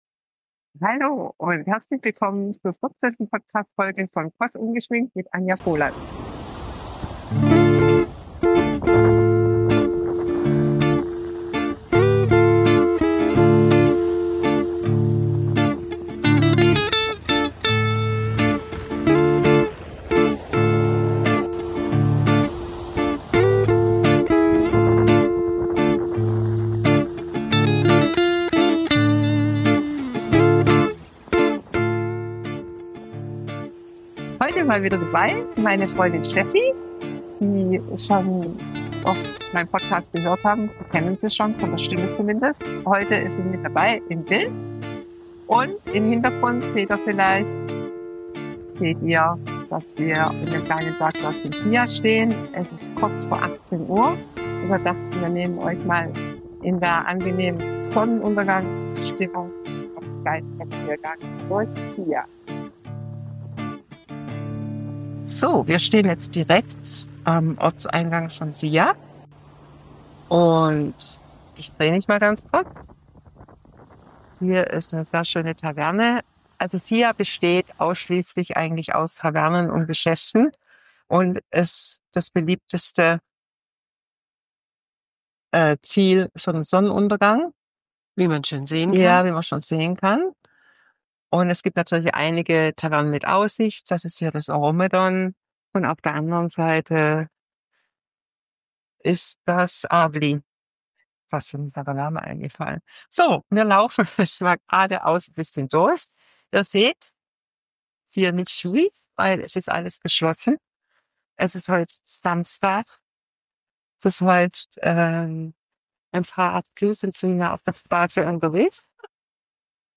Wir nehmen euch mit auf einen Spaziergang durch das Bergdörfchen Zia im Winter in der Sonnenuntergangsstimmung.